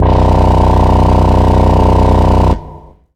SYNTHBASS2-R.wav